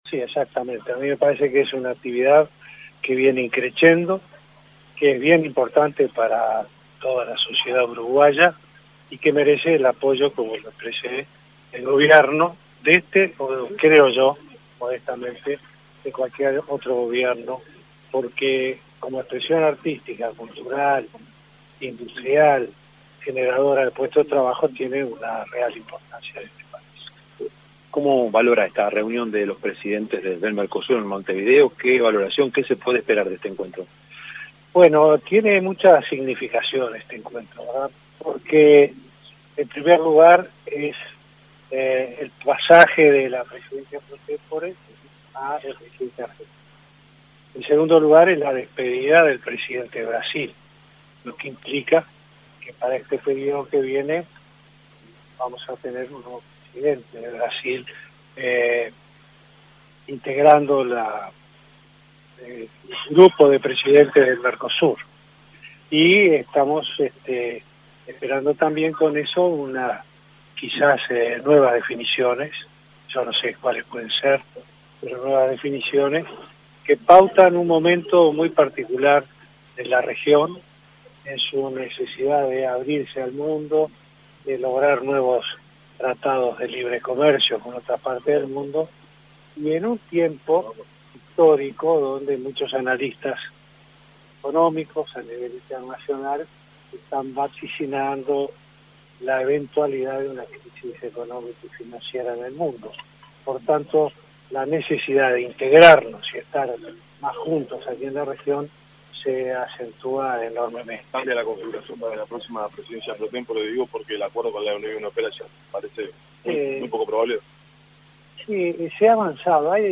El presidente Vázquez adelantó que el miércoles realizará un balance de gestión de los cuatro años de Gobierno en el almuerzo de ADM. Al participar de una conferencia de “Ronda Momo” en Daecpu, habló con la prensa del Mercosur y del puente de Carmelo.